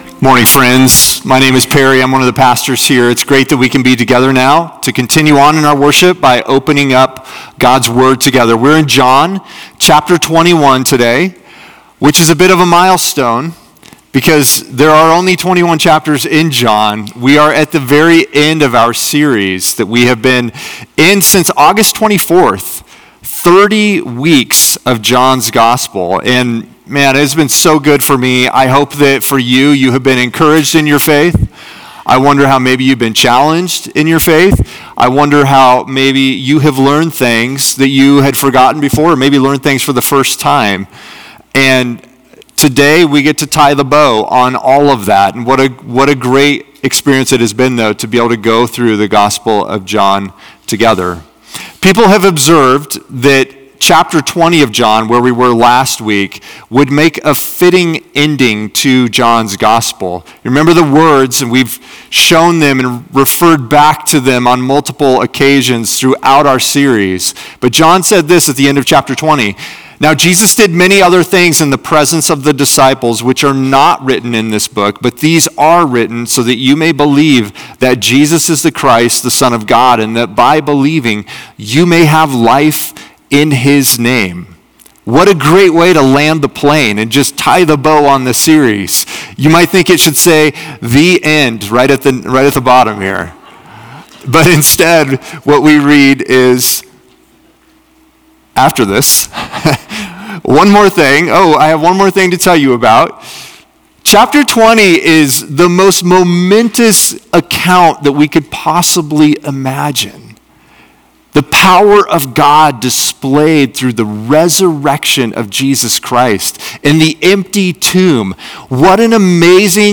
a thoughtful conversation through John 11, the story of Lazarus.